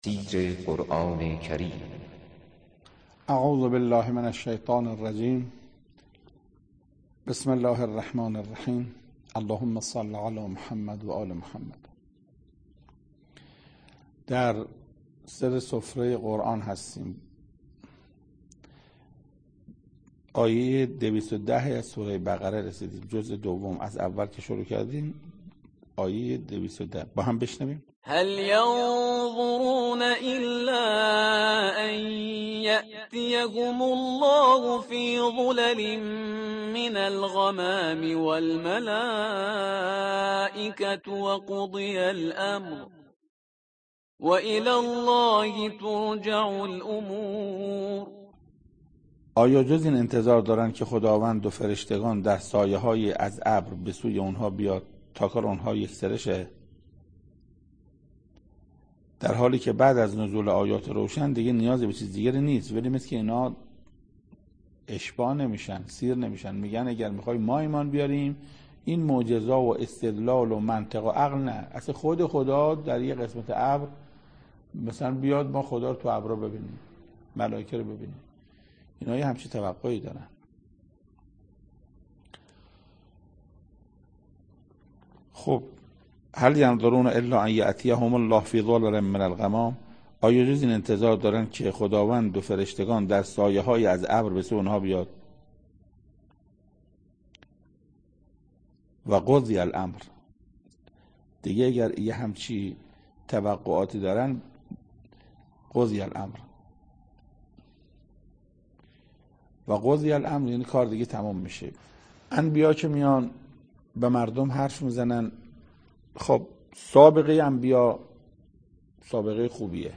سخنرانی محسن قرائتی